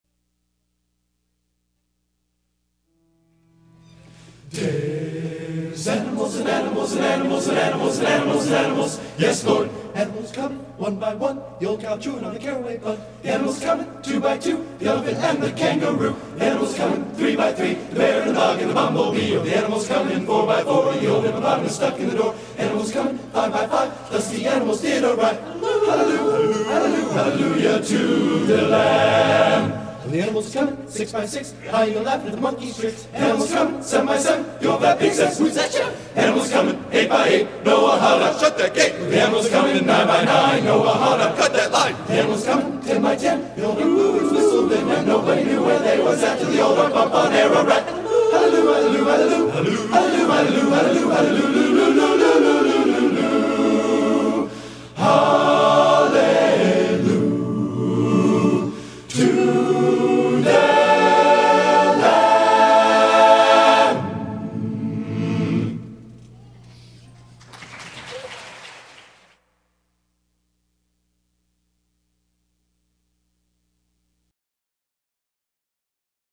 Genre: Spiritual | Type: